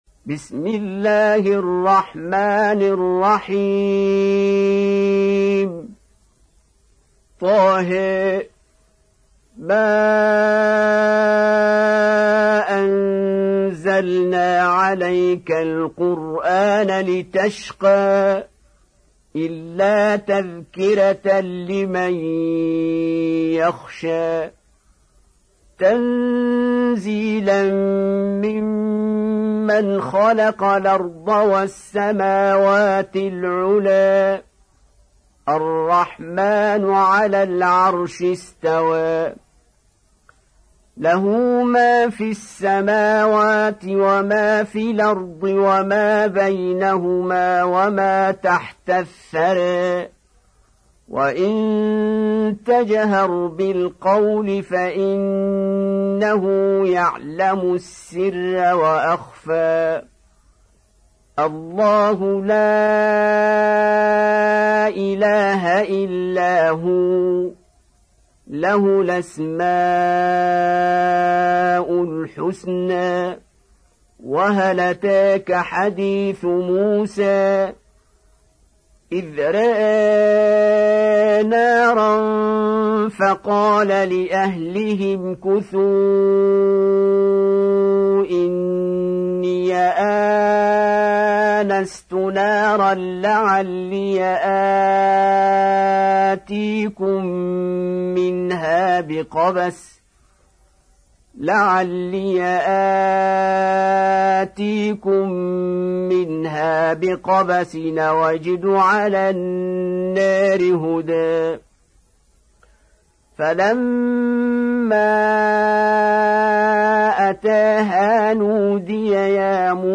Surah Taha Beautiful Recitation MP3 Download By Qari Abdul Basit in best audio quality.